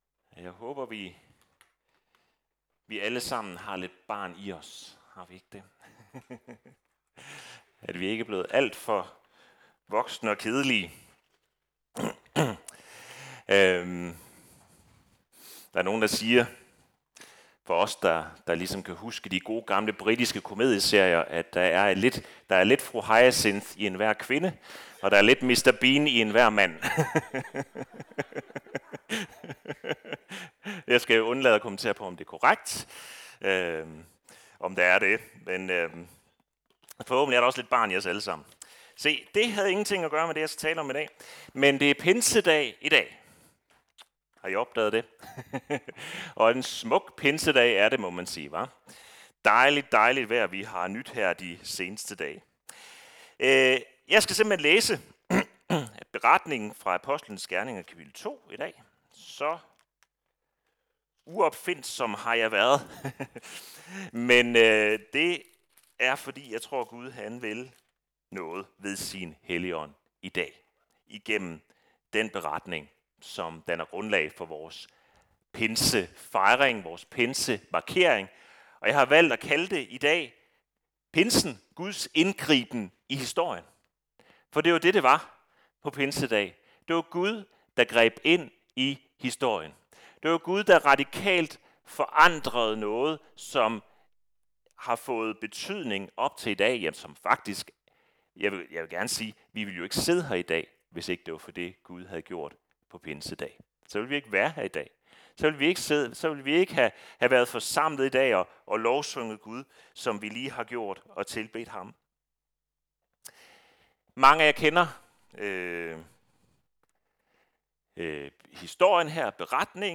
Pinse Gudstjeneste - Tønder Frikirke